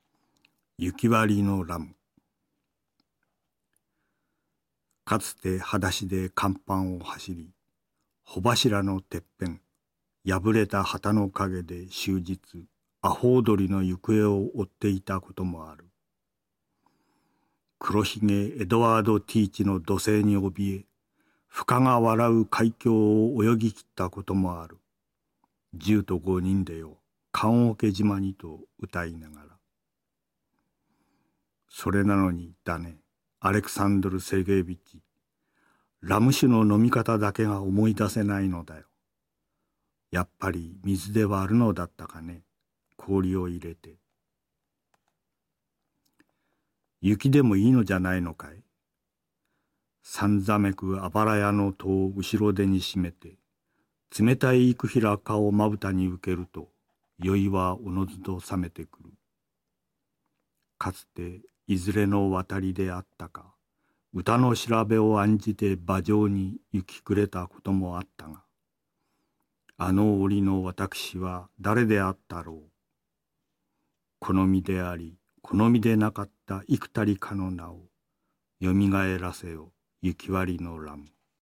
辻征夫の「雪わりのラム」を読む